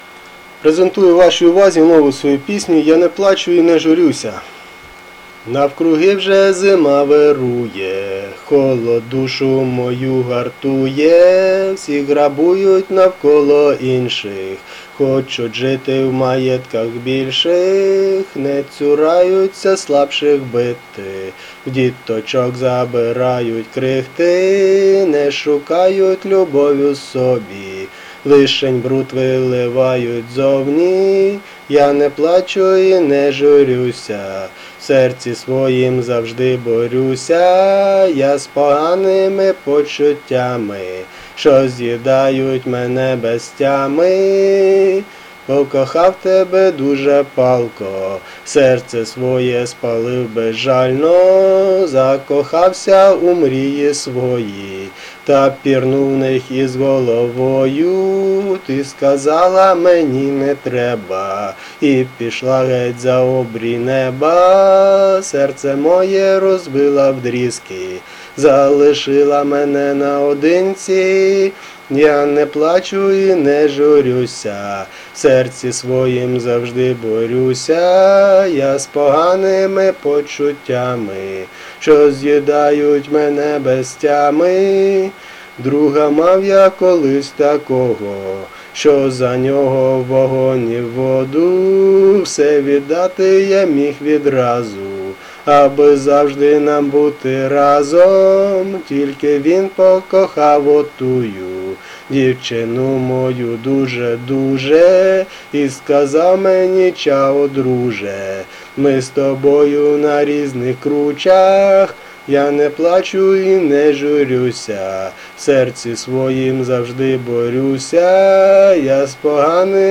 Рубрика: Поезія, Авторська пісня
Так пісня сумна, як наше життя інколи, але приспів оптимістичний. biggrin